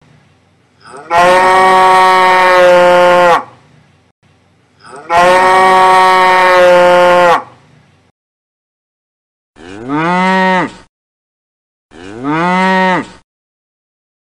効果音 牛 mp3